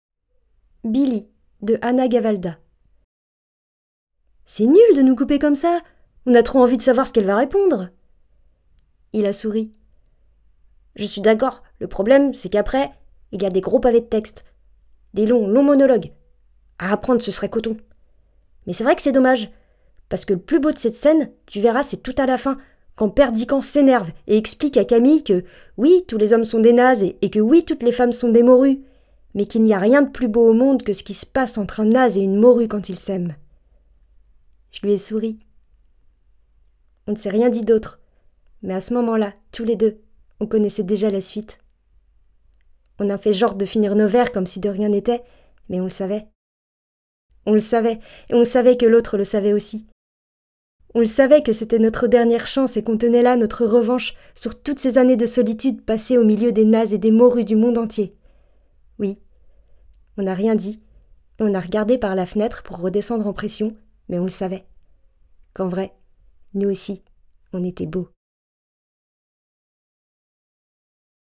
Narration Billie Anna Gavalda
14 - 34 ans - Soprano